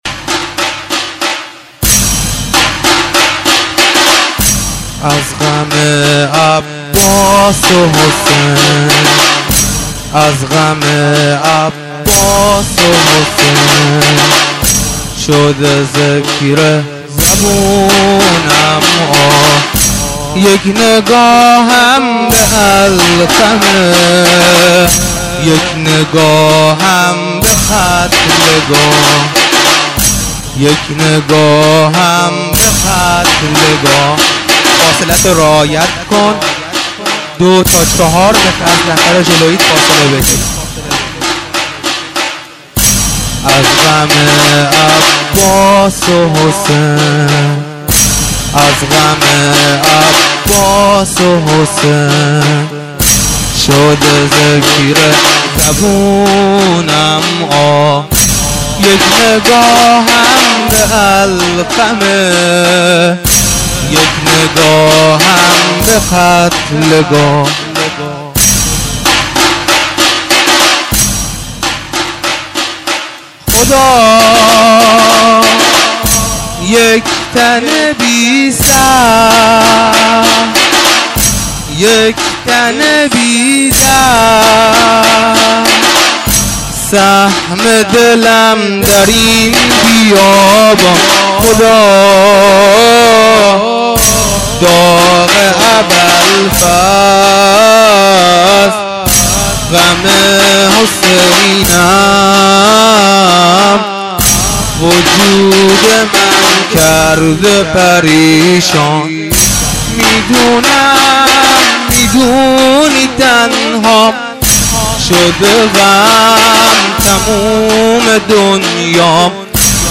زنجیرزنی مسجد سیدصالح محرم دزفول